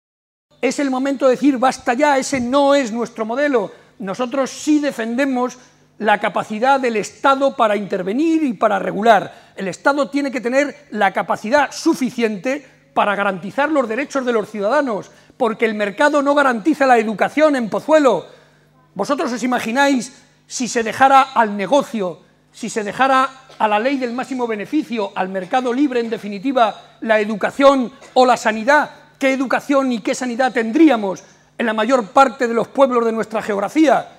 Barreda explicó durante un café-coloquio ante más de 250 personas en Pozuelo de Calatrava que la “brutal” crisis internacional, “que no tiene parangón”, excede de las posibilidades de un gobierno nacional o regional si actúa por sí sólo, por lo que se necesitará de la acción conjunta de la Unión Europea para salir adelante.
Cortes de audio de la rueda de prensa